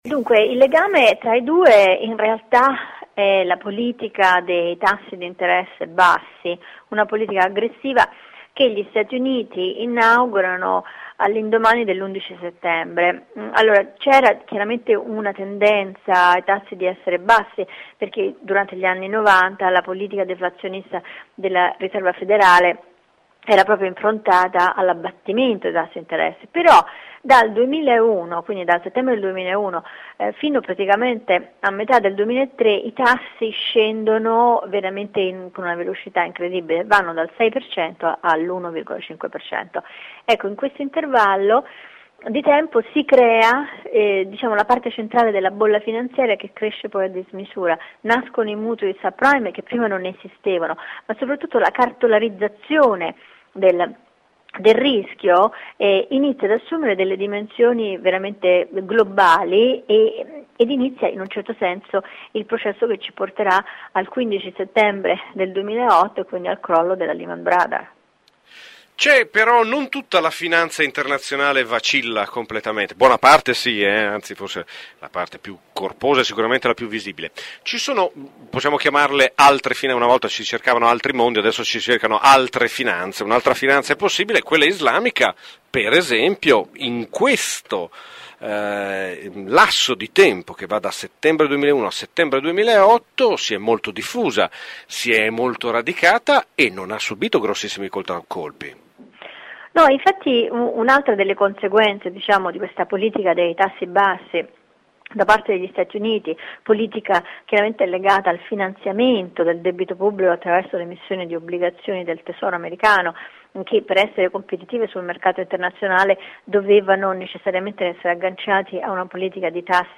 “La Morsa” della crisi. Intervista a Loretta Napoleoni